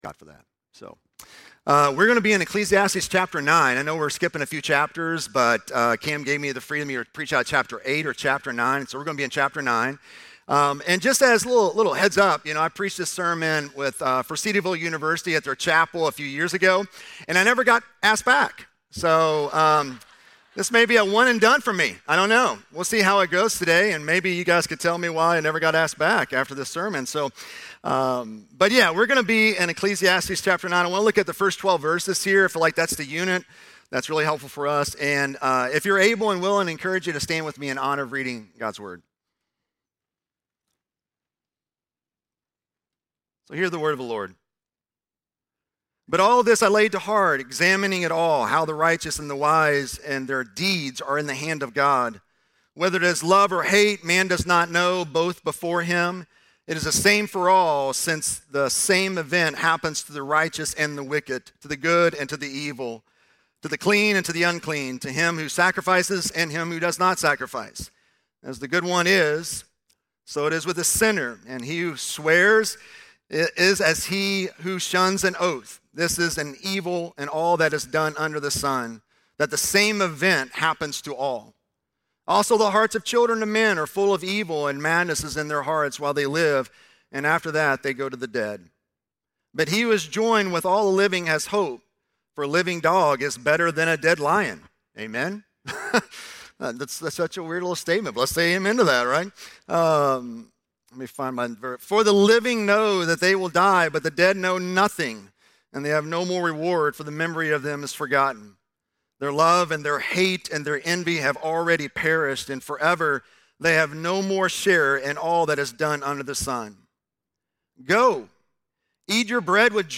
LaGrange Baptist Church Sermon podcast
2.9-sermon.mp3